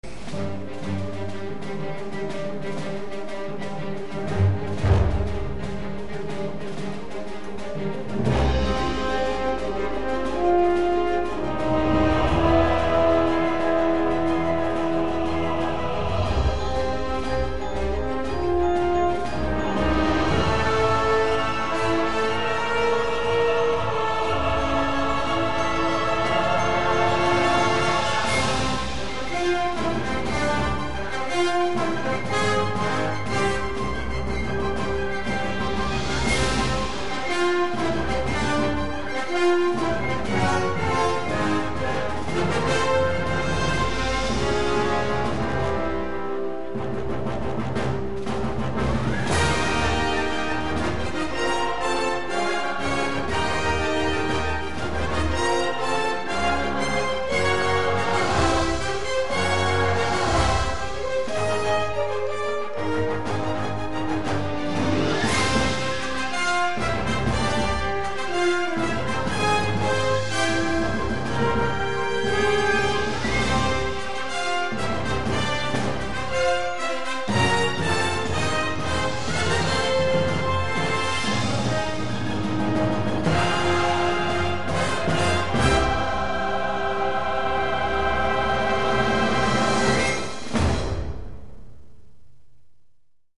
Music clip 3, 1'33" (1.06Mb) [End credits music]